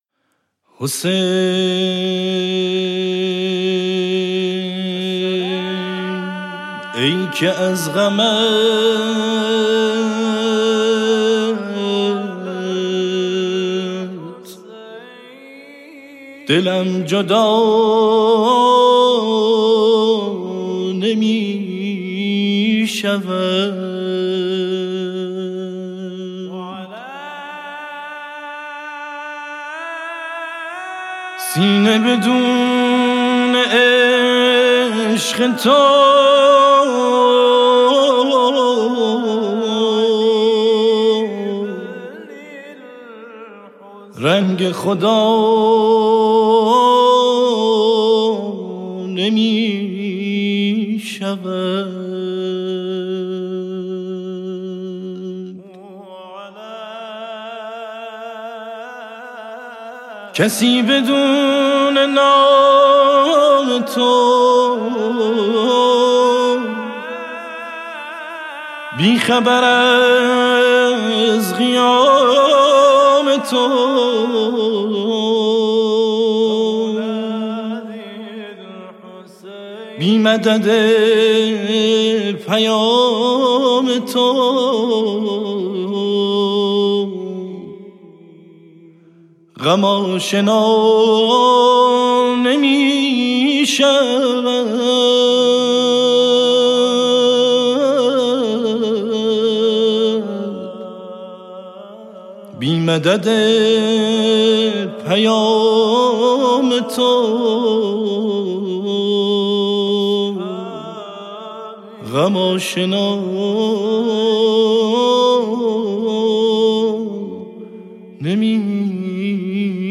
براساس فرم موسیقی آکاپلا